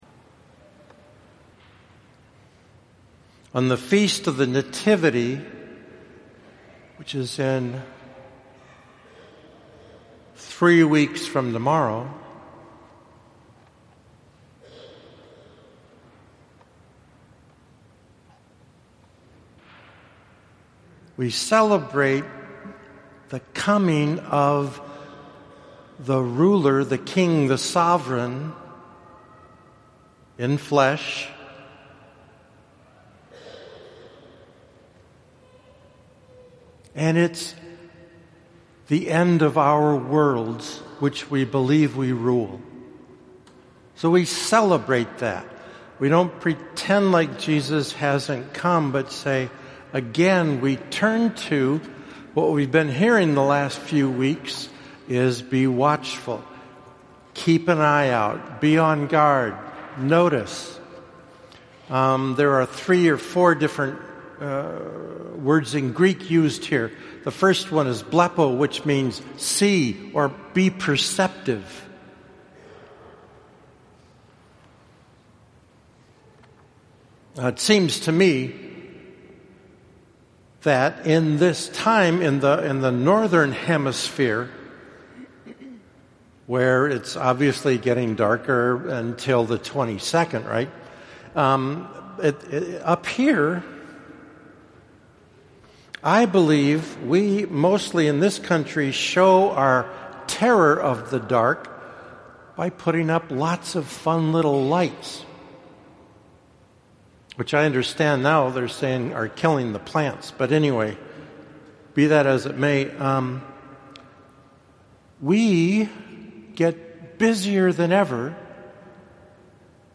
1st Sunday Advent – Homily (Audio)